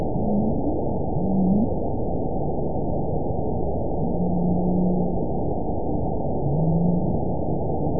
event 916001 date 12/22/22 time 04:10:05 GMT (3 years ago) score 8.10 location TSS-AB01 detected by nrw target species NRW annotations +NRW Spectrogram: Frequency (kHz) vs. Time (s) audio not available .wav